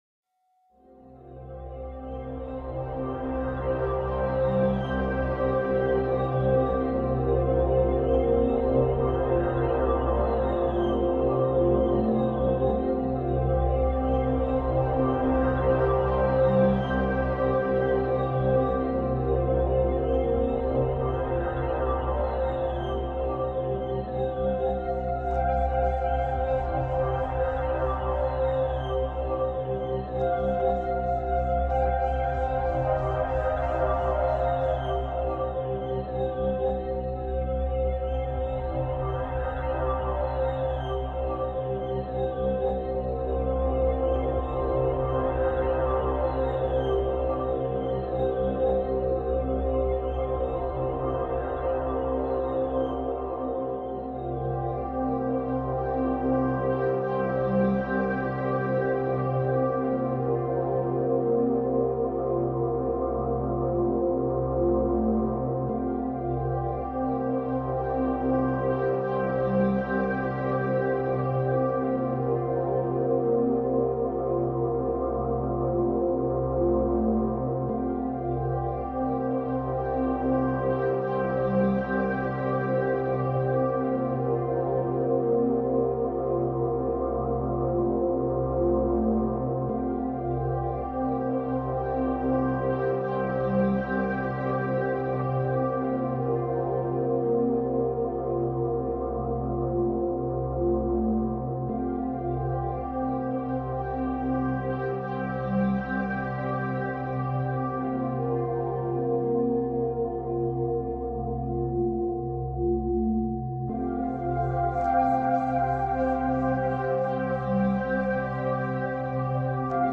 La fréquence 741 htz nettoie les cellules toxiques
FRÉQUENCES VIBRATOIRES
La-frequence-741-htz-nettoie-les-cellules-toxiques.mp3